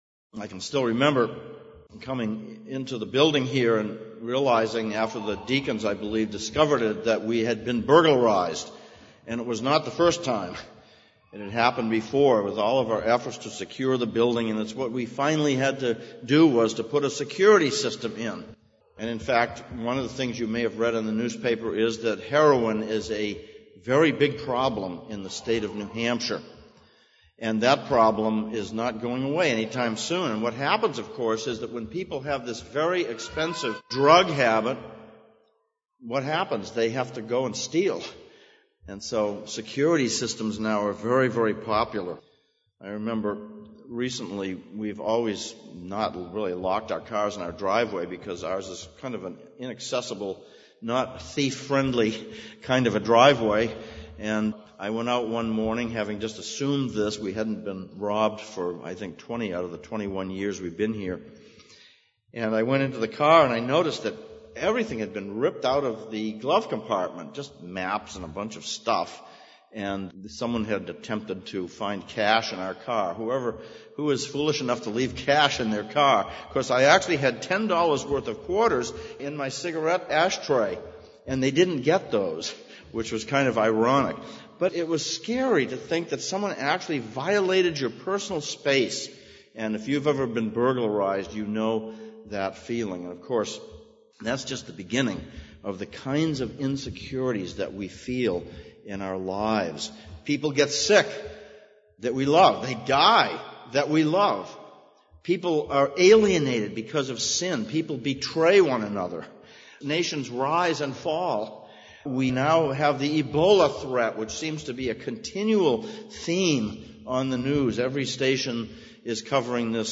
Psalms of Ascents Passage: Psalm 125:1-5, 2 Timothy 2:1-19 Service Type: Sunday Morning « 4.